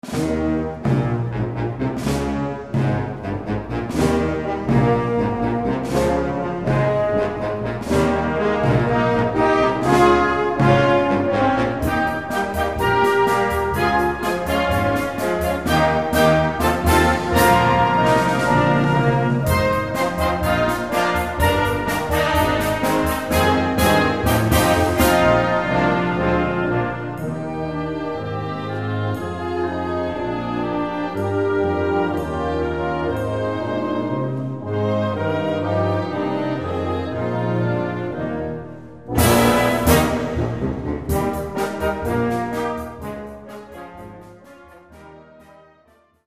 Concert Band ou Harmonie ou Fanfare